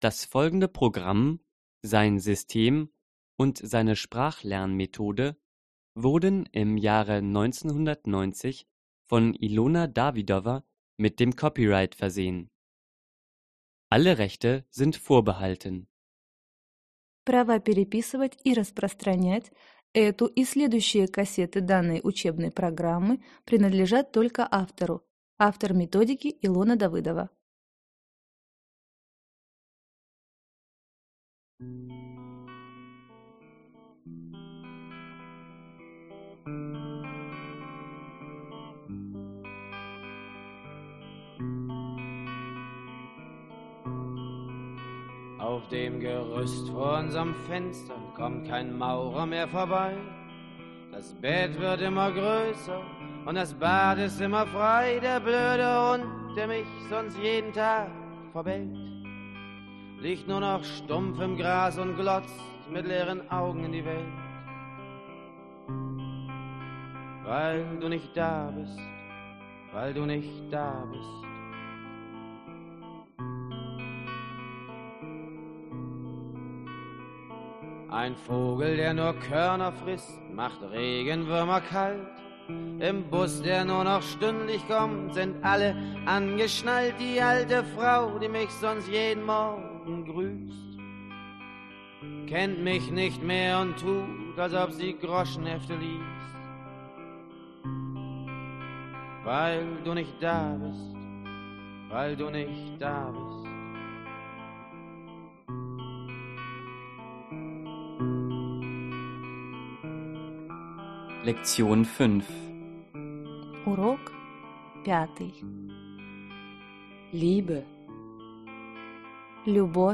Аудиокнига Разговорно-бытовой немецкий язык. Диск 5 | Библиотека аудиокниг